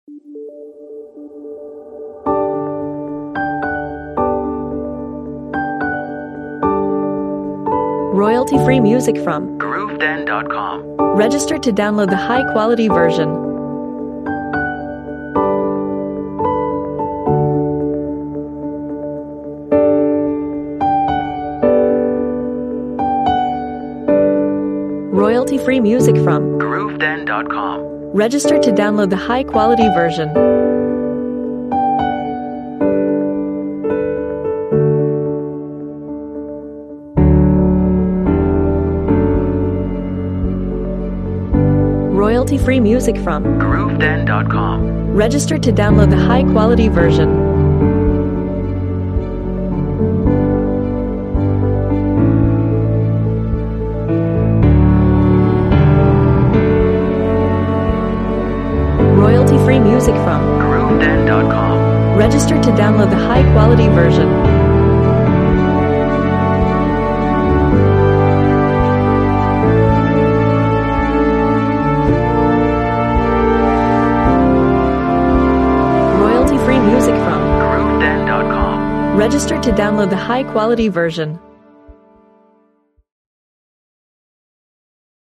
Beautiful piano with dynamic orchestral culmination.